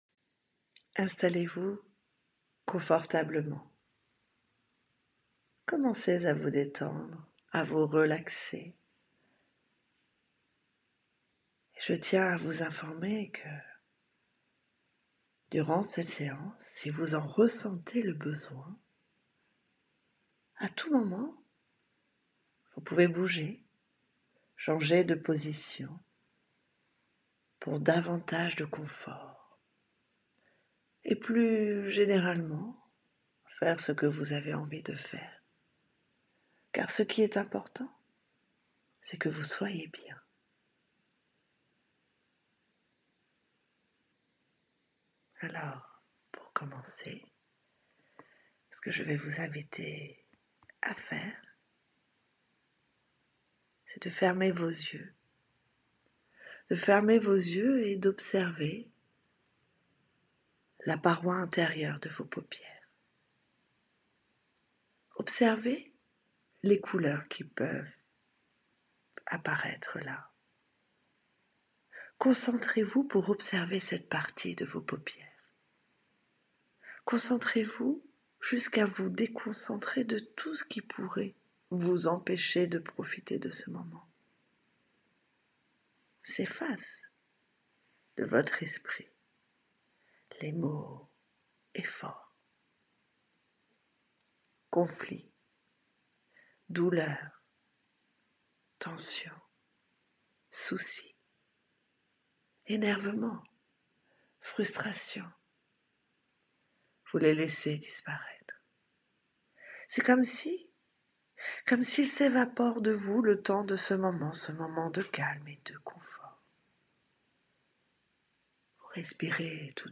Ces séances préenregistrées sont conçues pour aider le plus grand nombre de personnes, elles ne sont pas personnalisées comme c’est le cas lors de mes consultations d’hypnothérapie mais cela vous permet de découvrir les bienfaits de l’hypnose dans le confort de votre domicile.